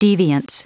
Prononciation du mot deviance en anglais (fichier audio)
Prononciation du mot : deviance